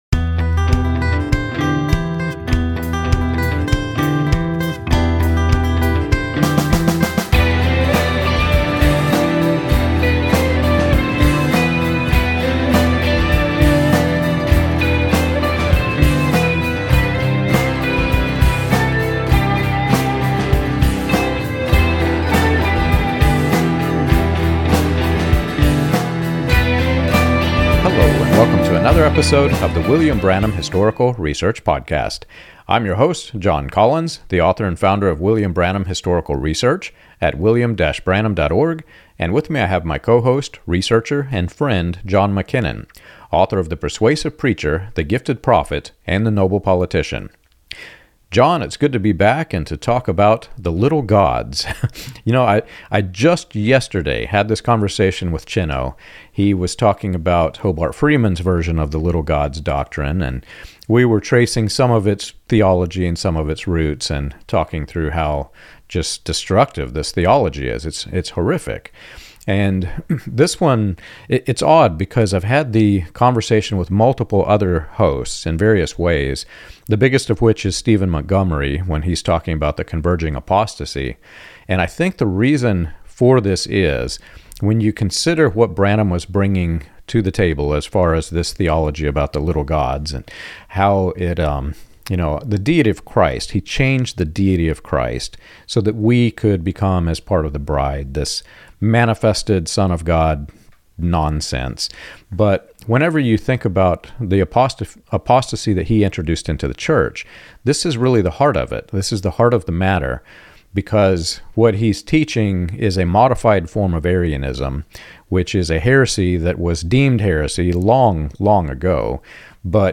This discussion highlights how these teachings fractured families, eroded biblical clarity, and set the stage for modern charis